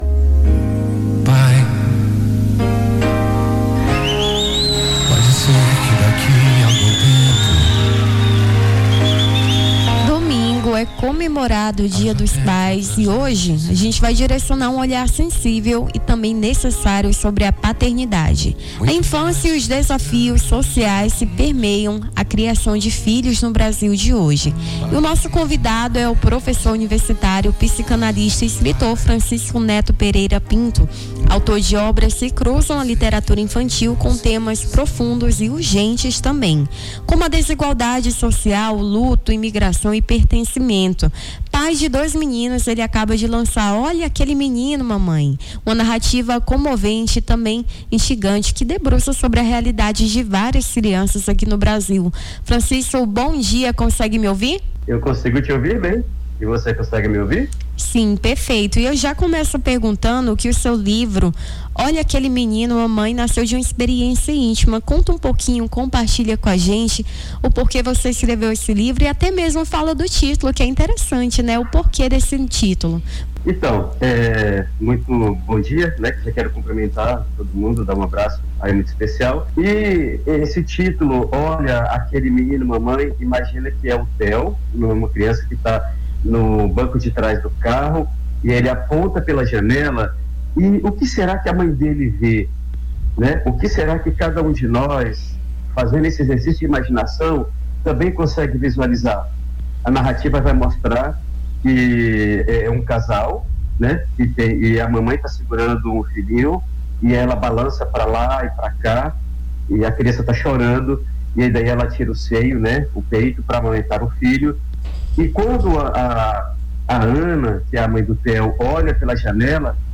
Baixar Esta Trilha Nome do Artista - CENSURA - ENTREVISTA DIA DOS PAIS (08-08-25).mp3 Foto: Freepik/internet Facebook Twitter LinkedIn Whatsapp Whatsapp Tópicos Rio Branco Acre Dia dos Pais Literatura